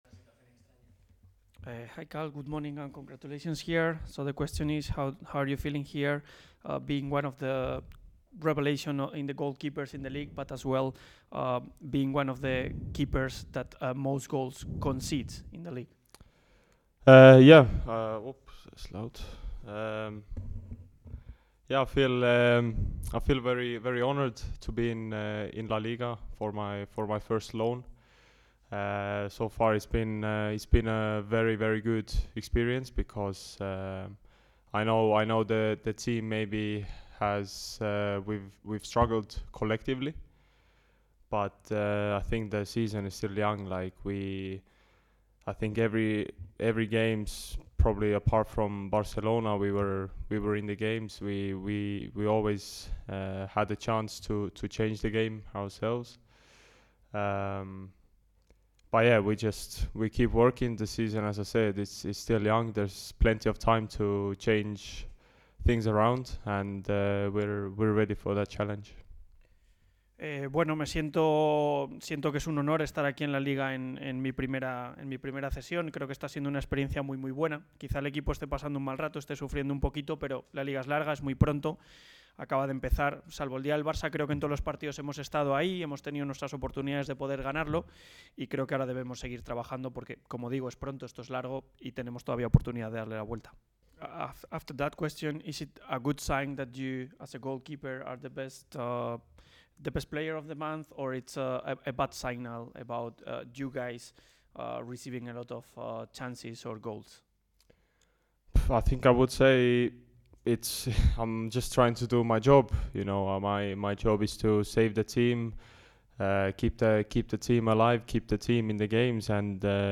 “Quiero seguir mejorando, busco la perfección. He tenido un progreso individual, pero tengo que seguir trabajando para demostrarlo en el campo”, expresó el cancerbero en la posterior rueda de prensa.